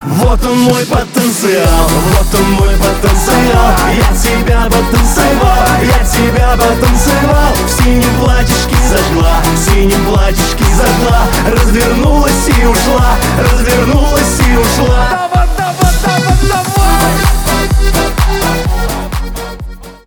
поп
аккордеон